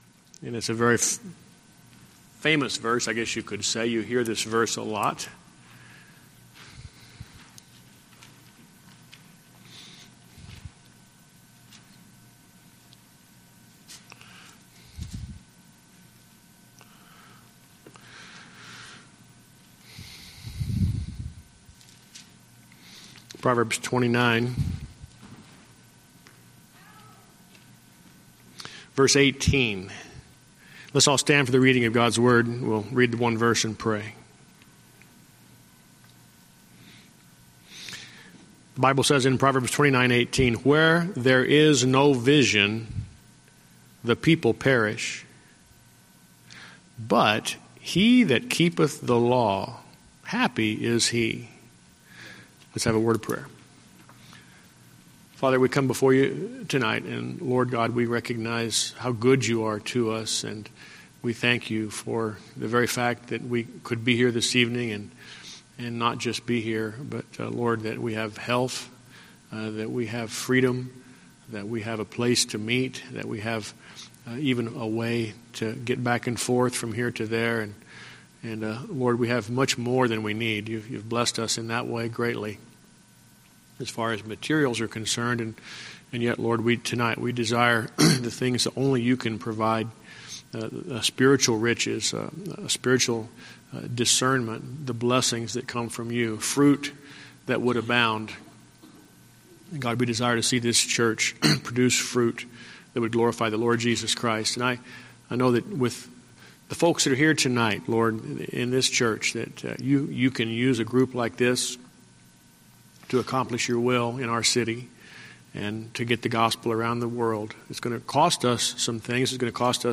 Fundamental Independent Baptist Church Glendale Arizona Luke AFB
Series: Guest Speaker